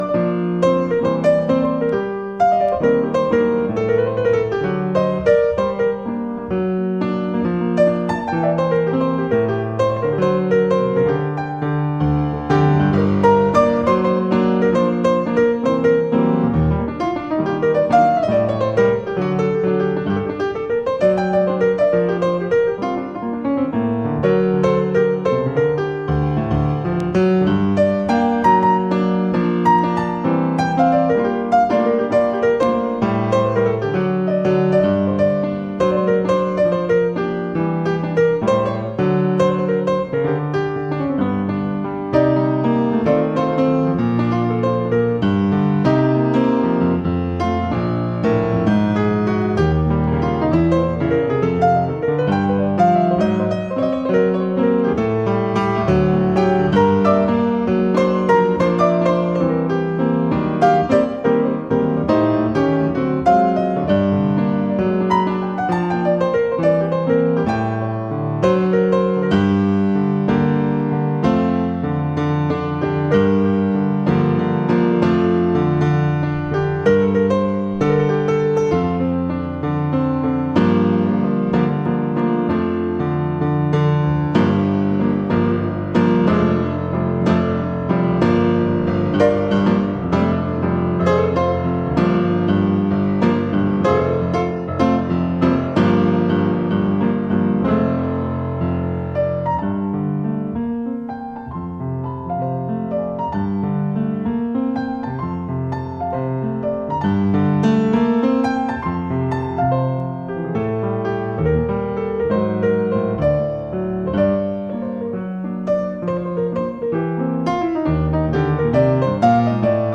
a lovely adventure into bop
the whole thing has been mastered at half speed